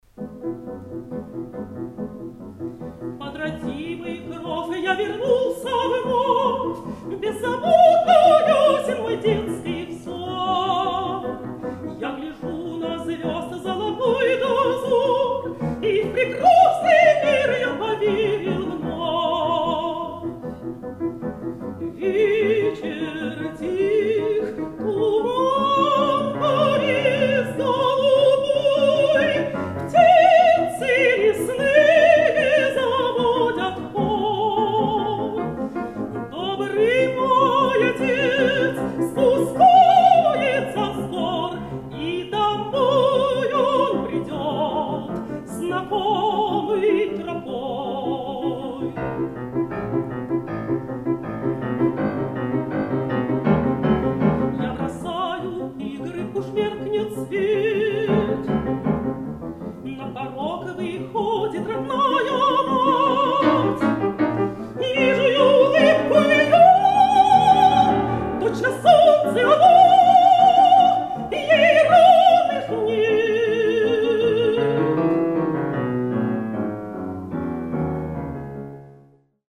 Партия фортепиано